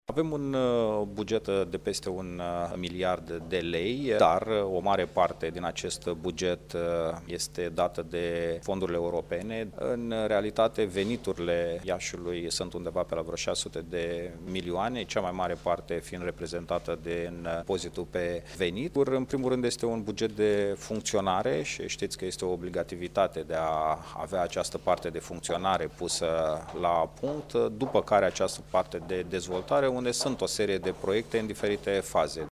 După 4 ore de dezbateri, bugetul municipiului Iaşi a fost aprobat, astăzi, în şedinţa Consiliului Local.
Reprezentantul ALDE, viceprimarul Radu Botez, a atras atenţia că un semnal de alarmă îl reprezintă faptul că din cele 1,5 miliarde de lei, veniturile proprii ale municipalităţii sunt foarte mici, de numai 600 de milioane.